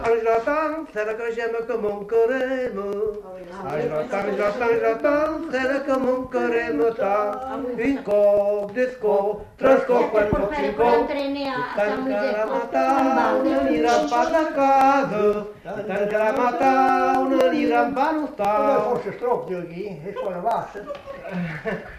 Aire culturelle : Agenais
Genre : chant
Effectif : 2
Type de voix : voix d'homme
Production du son : chanté